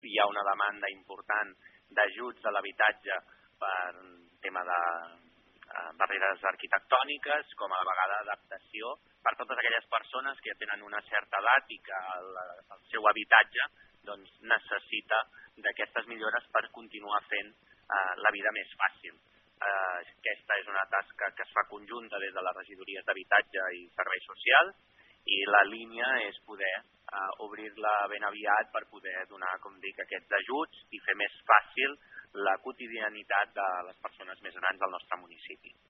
Ho explica Jofre Serret, regidor de Serveis Socials de Malgrat.